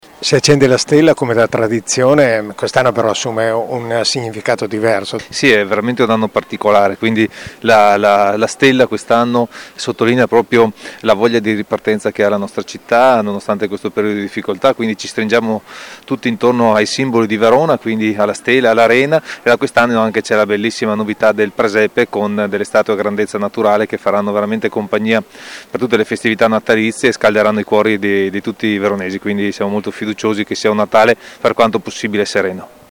Le interviste del nostro corrispondente
assessore alle Manifestazioni Filippo Rando
assessore-alle-Manifestazioni-Filippo-Rando-allaccensione-della-stella-di-Natale.mp3